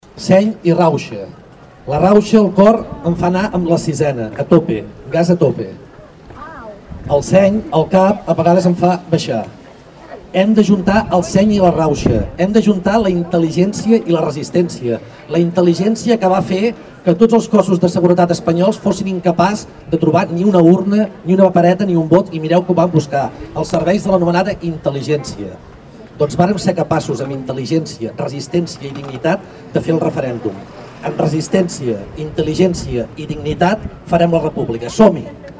Eren les 8 del vespre passades quan la marxa arribava a les Escoles Velles on s’hi van fer parlaments per part de un membre del CDR, una altra de la plataforma 1 d’octubre i finalment de l’alcalde de la Bisbal.
Lluís Sais, alcalde de la Bisbal